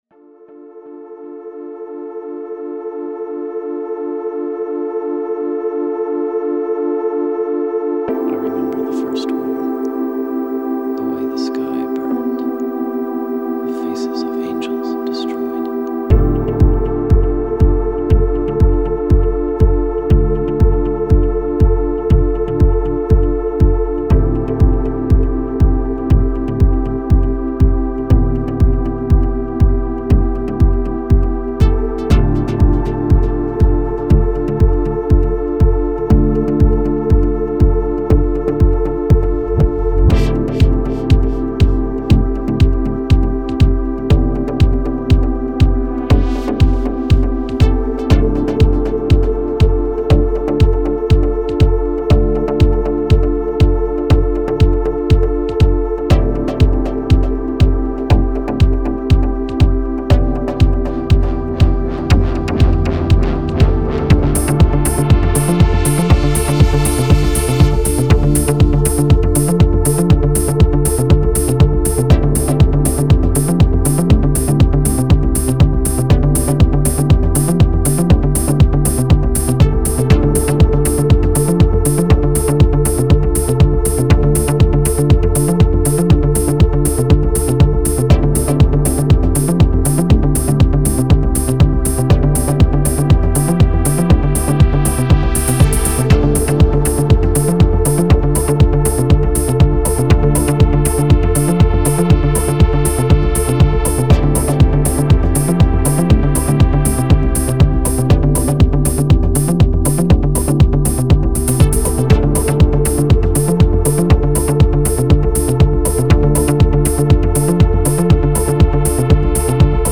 dance/electronic
Techno
Ambient
Trance